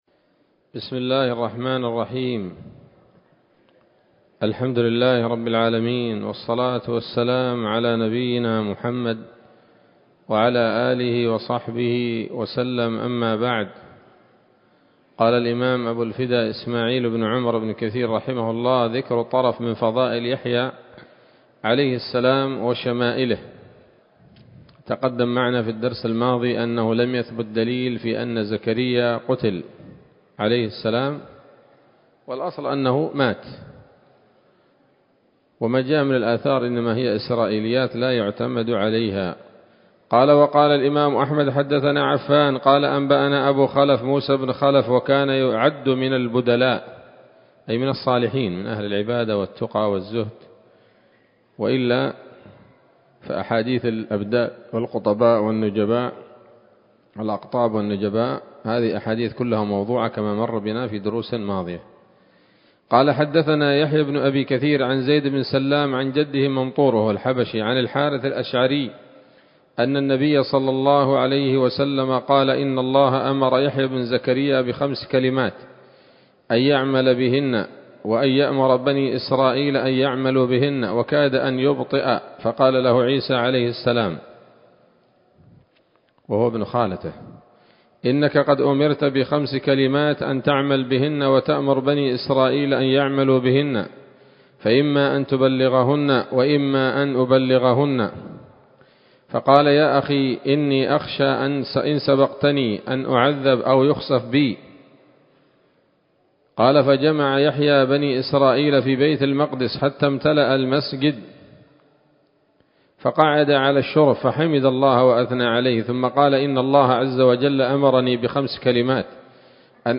‌‌الدرس الخامس والثلاثون بعد المائة من قصص الأنبياء لابن كثير رحمه الله تعالى